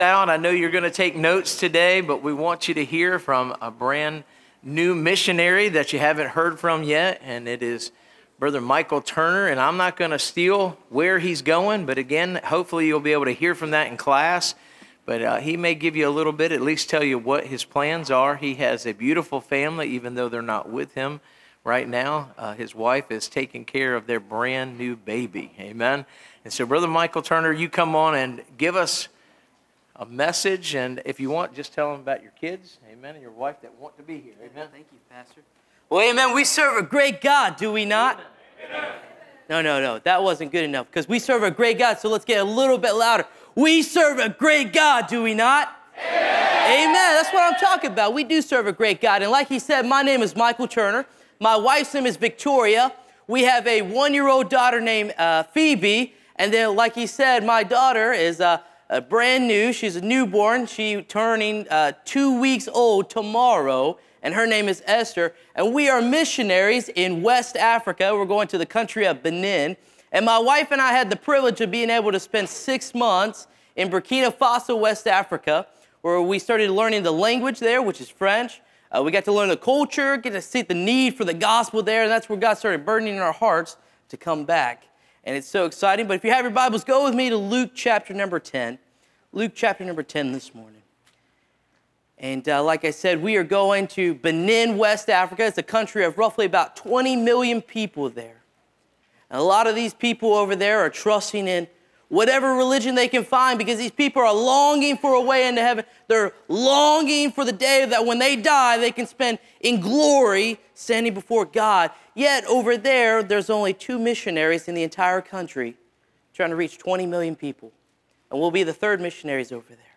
Series: (Missions Conference 2025)
Preacher